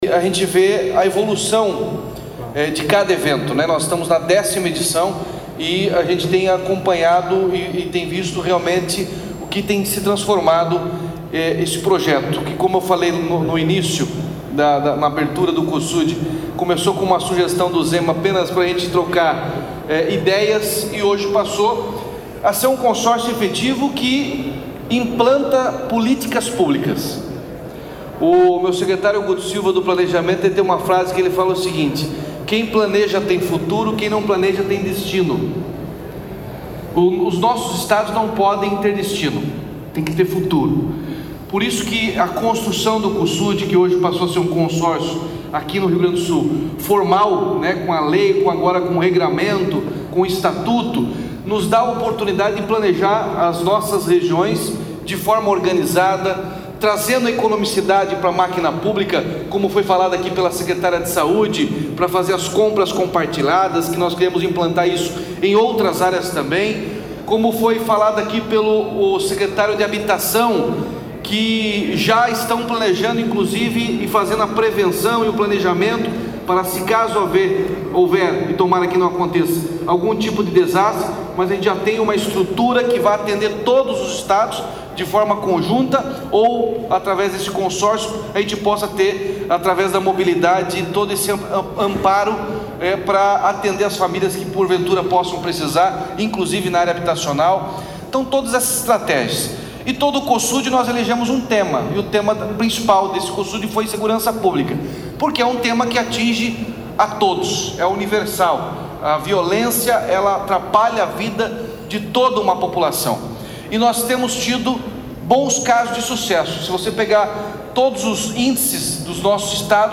Sonora do governador Ratinho Junior sobre a Carta da 10º Reunião do Cosud, em Porto Alegre